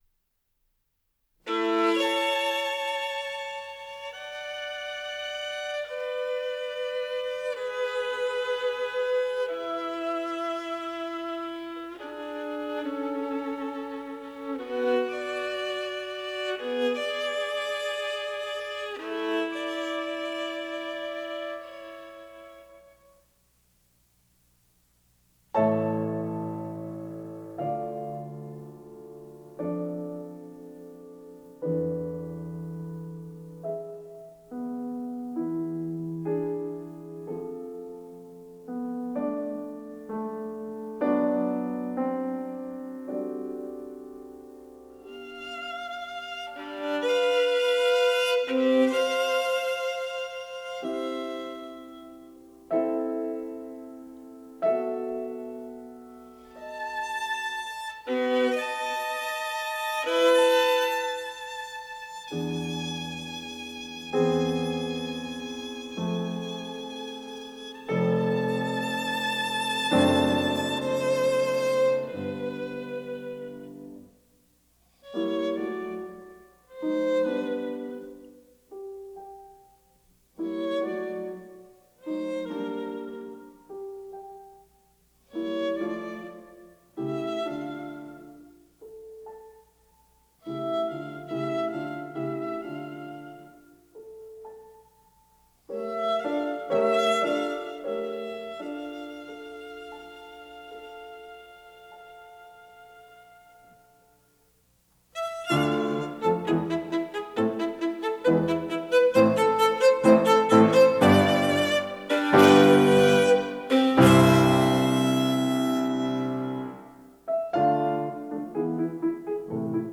Studio de Le Chant du Monde, Paris.